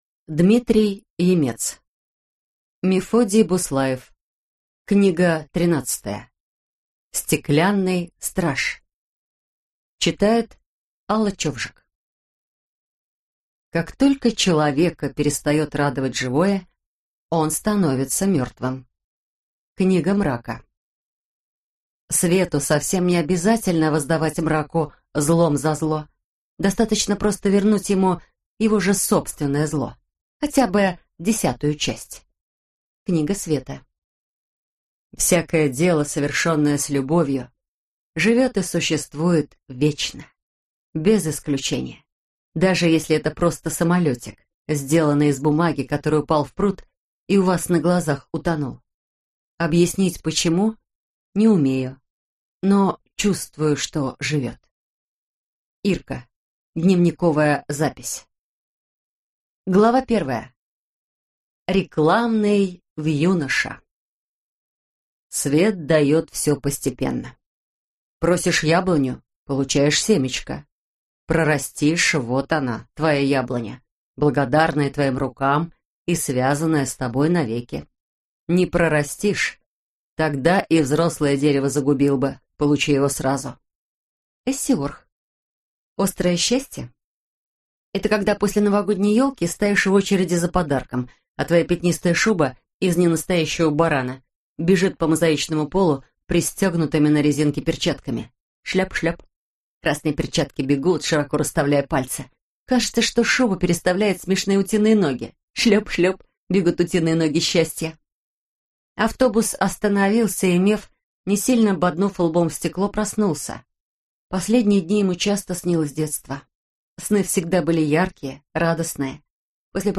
Аудиокнига Стеклянный страж | Библиотека аудиокниг